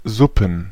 Ääntäminen
Ääntäminen Tuntematon aksentti: IPA: /ˈzʊpm̩/ IPA: /ˈzʊpən/ Haettu sana löytyi näillä lähdekielillä: saksa Käännöksiä ei löytynyt valitulle kohdekielelle. Suppen on sanan Suppe monikko.